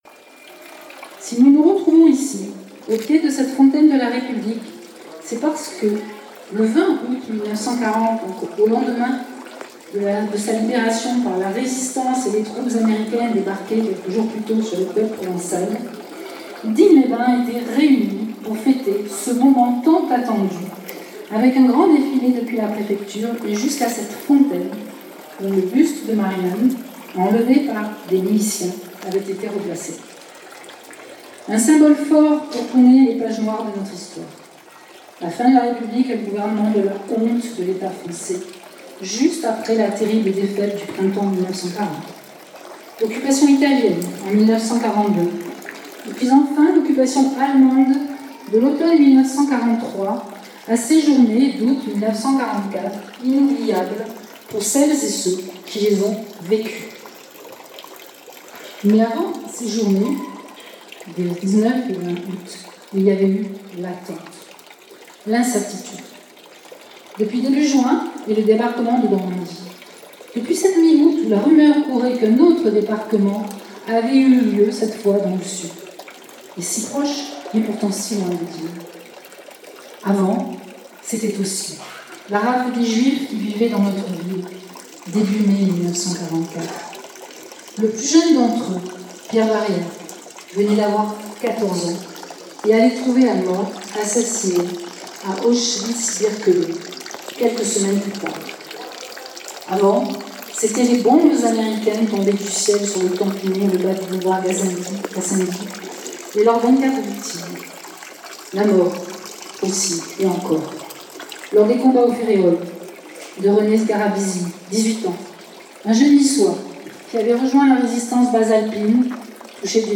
Discours cérémonie commémoration de la libération de Digne du 19 aout 1944.mp3 (17.66 Mo)